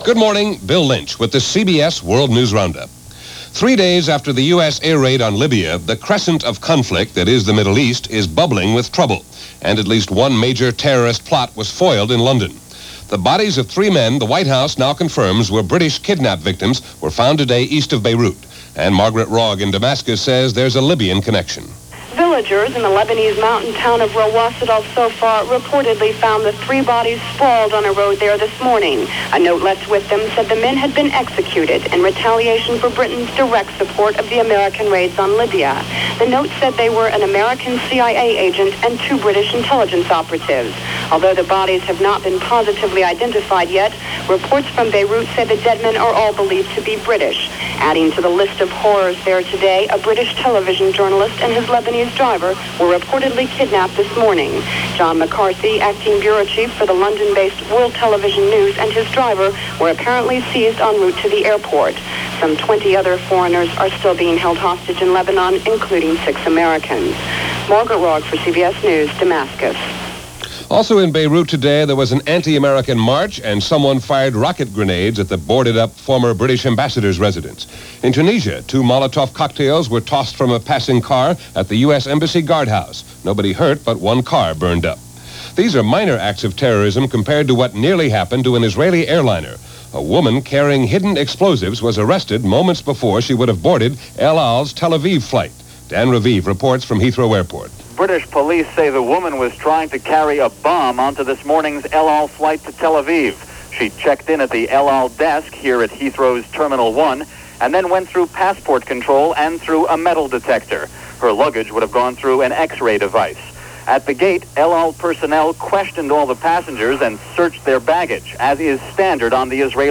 And aside from the continuing fallout from the Libyan air strike that’s a tiny fraction of what went on this April 17th in 1986 as reported by The CBS World News Roundup.